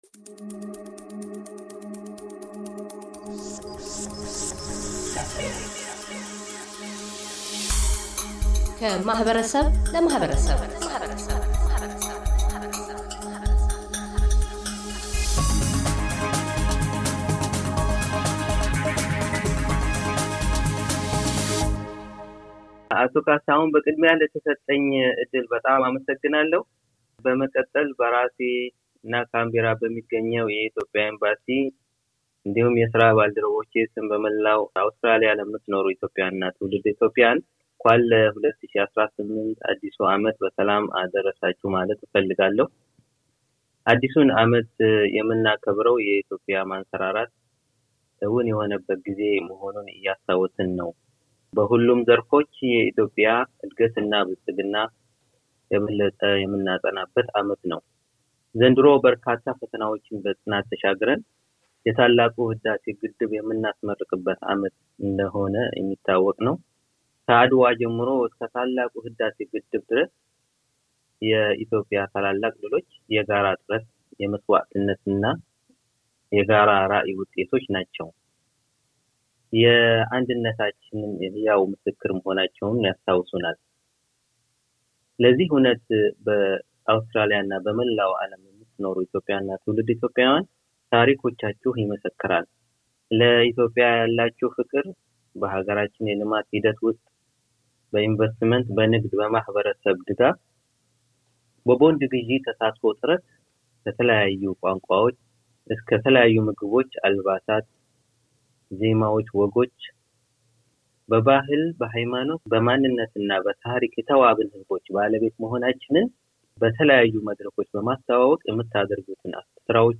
አኑዋር ሙክታር፤ በአውስትራሊያ የኢትዮጵያ ኤምባሲ ተጠባባቂ አምባሳደር፤ የ2018 የኢትዮጵያ አዲስ ዓመት እንኳን አደረሳችሁ መልዕክት።